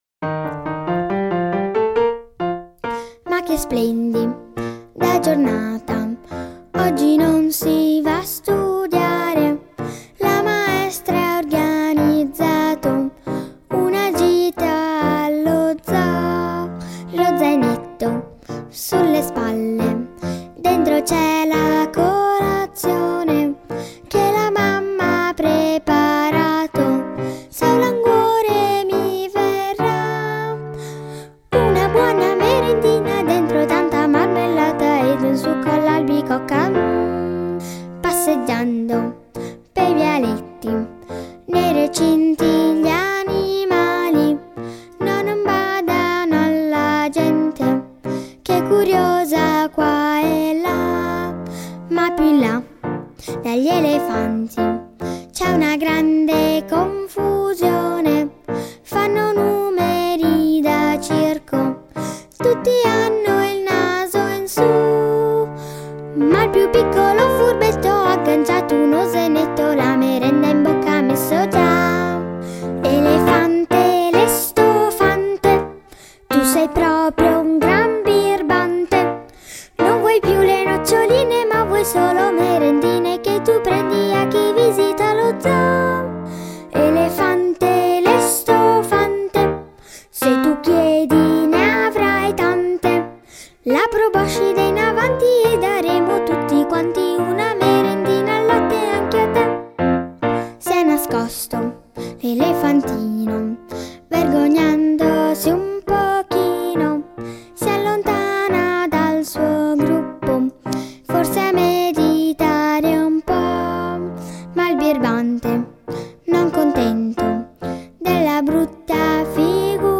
canzoni per bambini